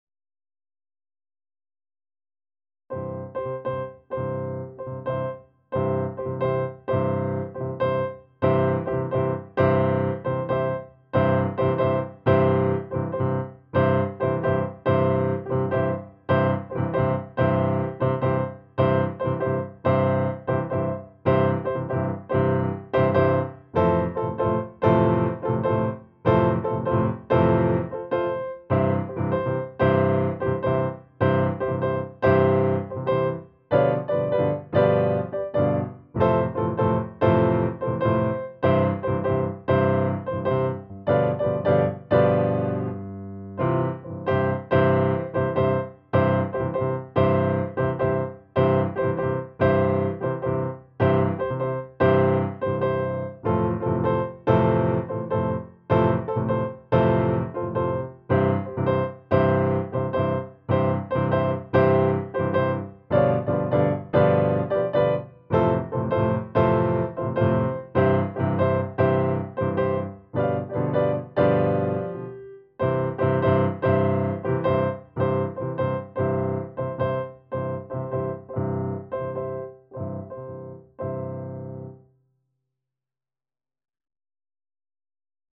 Here are some recordings of 12-bar blues played with methods taught in this book: